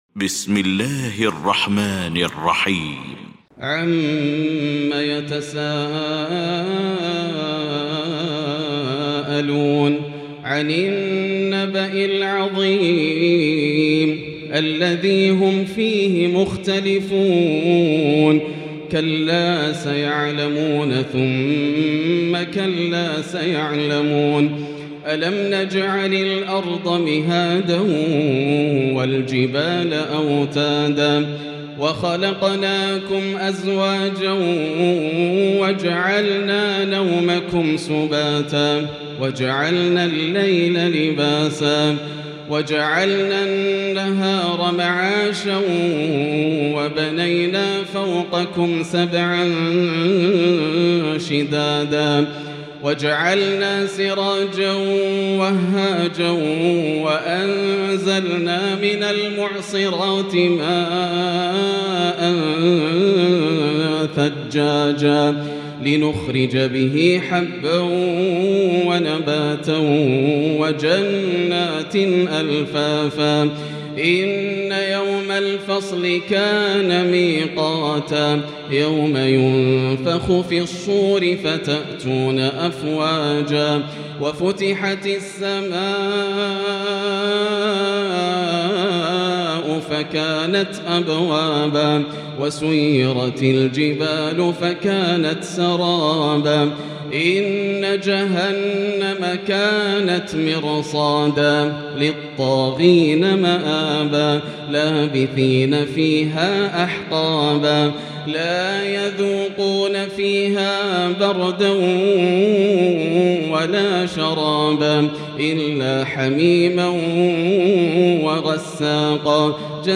المكان: المسجد الحرام الشيخ: فضيلة الشيخ ياسر الدوسري فضيلة الشيخ ياسر الدوسري النبأ